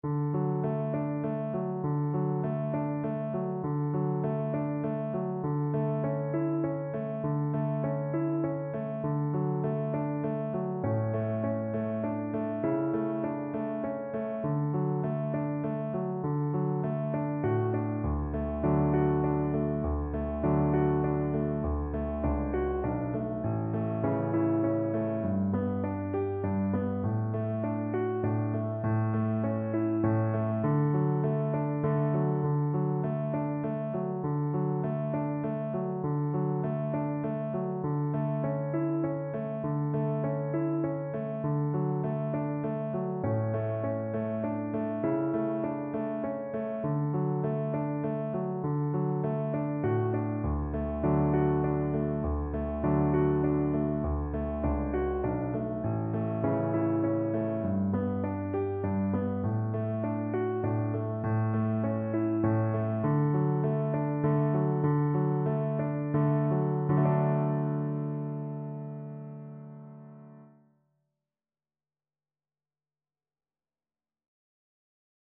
Play (or use space bar on your keyboard) Pause Music Playalong - Piano Accompaniment Playalong Band Accompaniment not yet available transpose reset tempo print settings full screen
D major (Sounding Pitch) (View more D major Music for Voice )
3/4 (View more 3/4 Music)
Gently =c.100
Traditional (View more Traditional Voice Music)